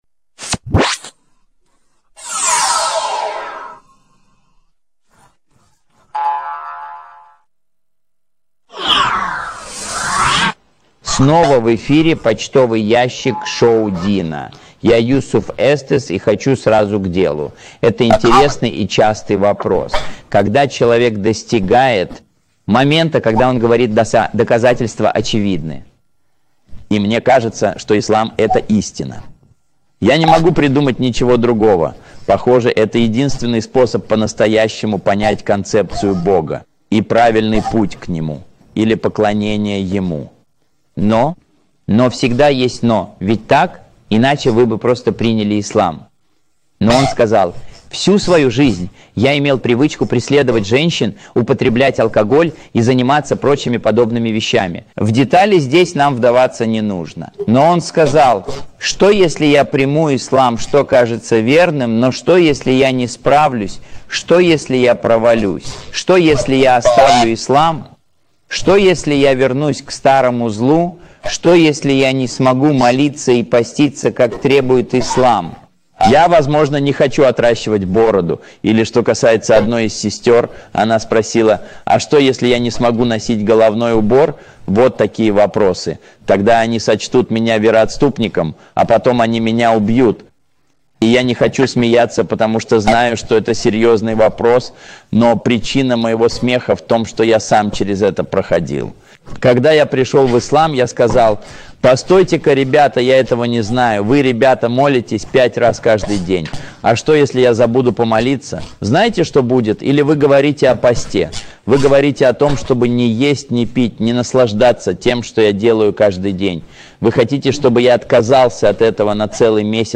в этой лекции!